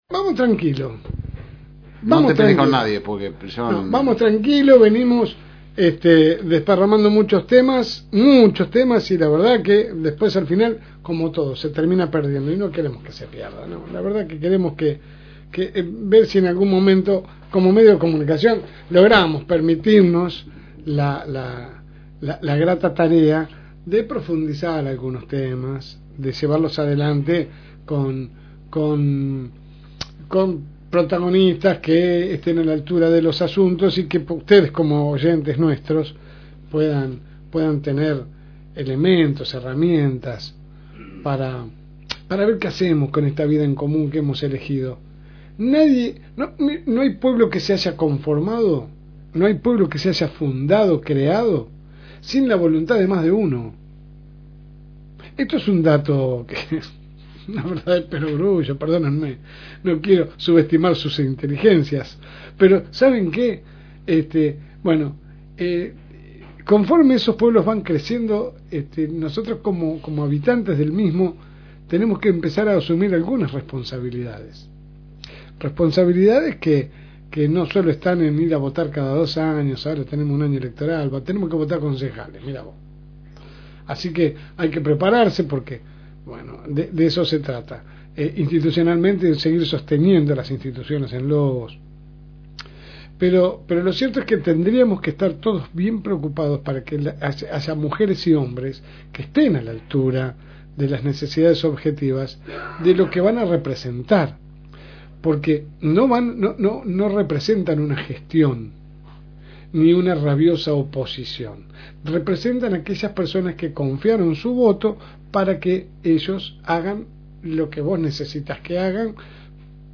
AUDIO – Editorial de LSM